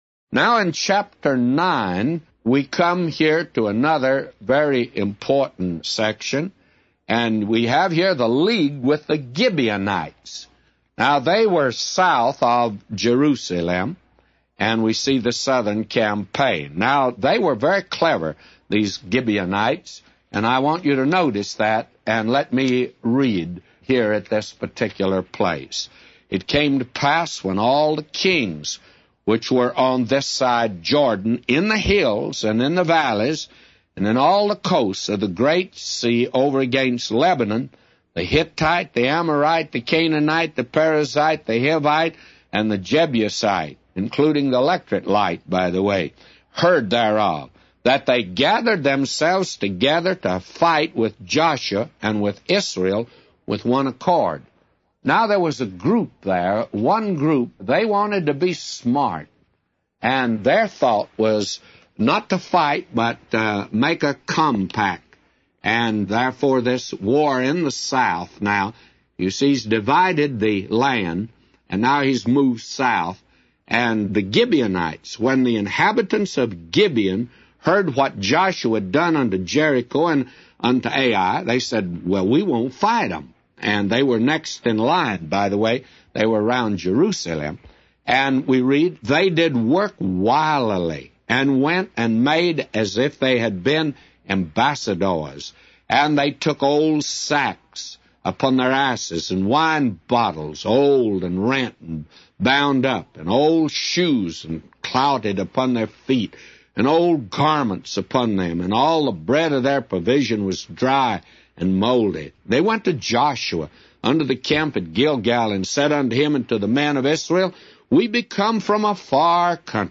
A Commentary By J Vernon MCgee For Joshua 9:1-999